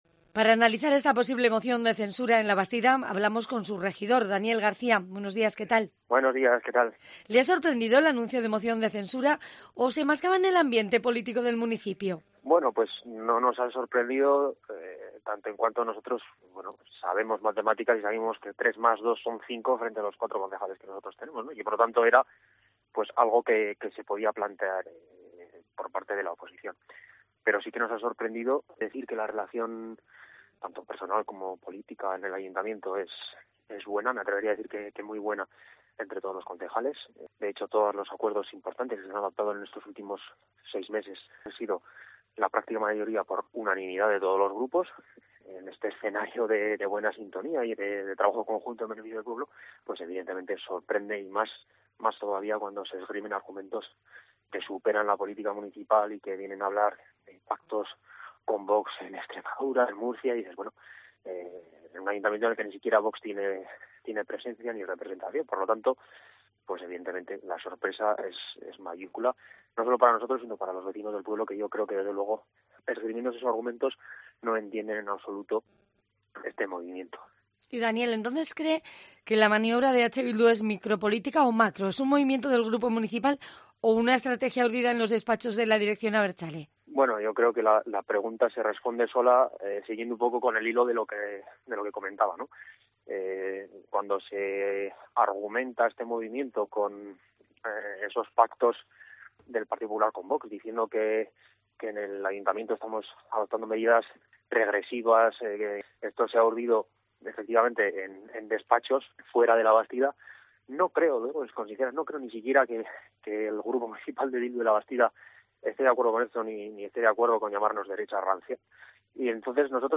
Daniel García, alcalde de Labastida, entrevistado en COPE Euskadi